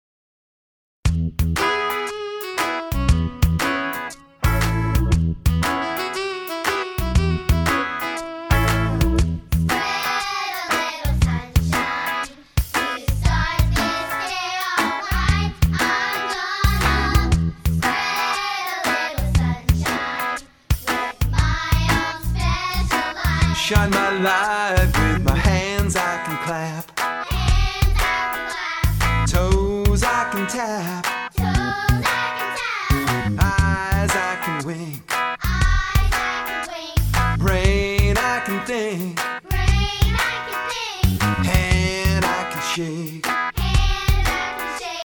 a children’s chorus